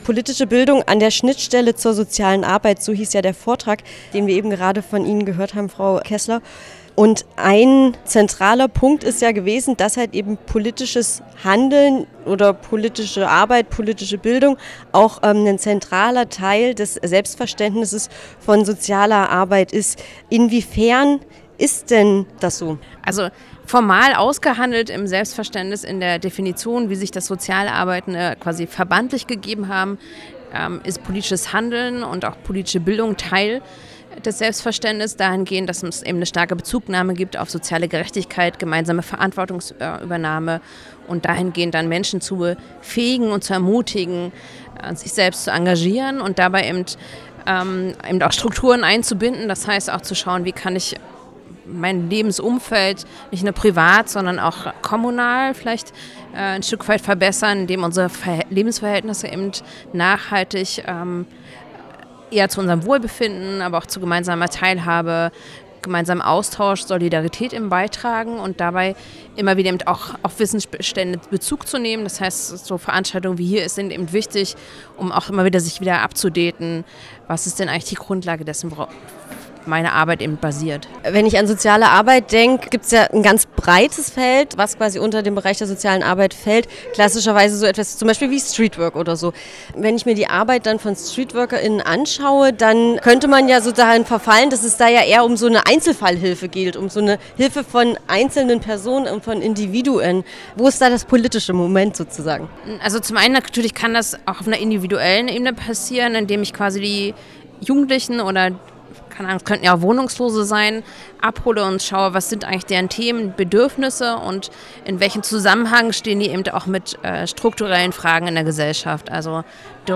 Fachtag "Demokratie erlebbar machen" | Interviews zum Nachhören